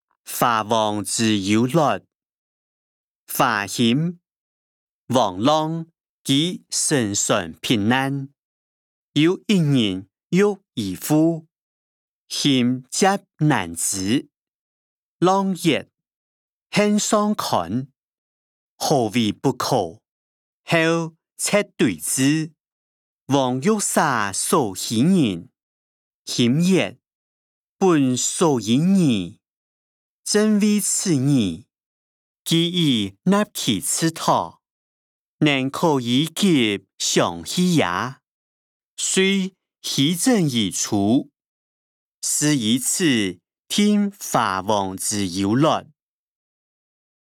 小說-華、王之優劣音檔(四縣腔)